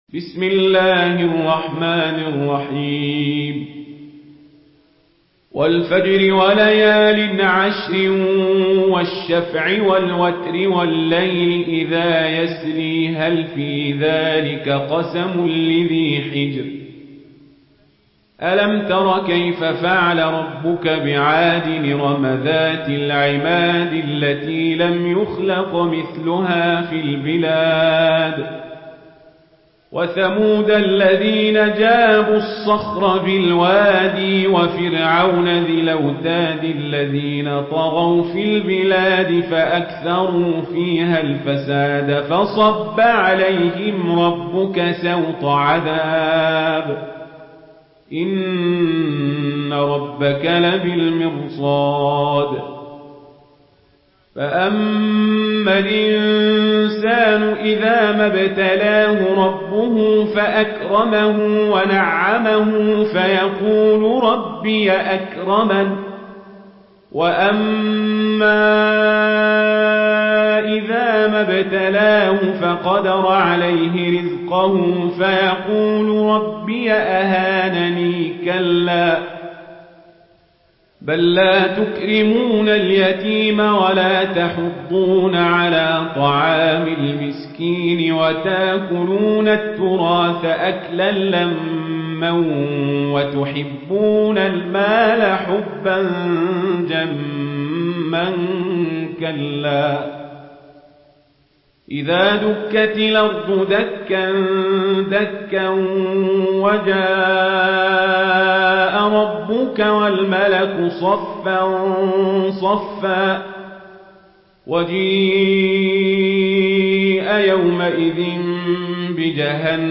مرتل ورش عن نافع